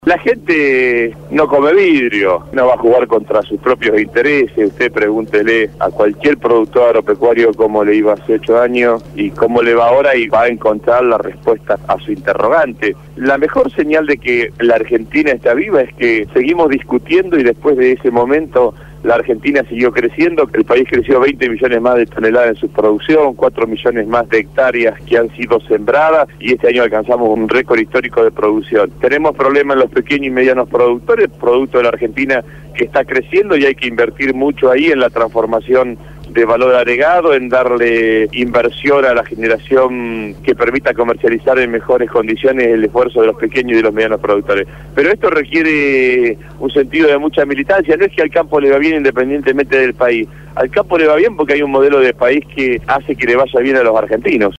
El Ministro de Agricultura de la Nación y candidato a Diputado Nacional por la Provincia de Buenos Aires acompañó a Cristina Fernandez en la Fiesta del Maíz en Chacabuco.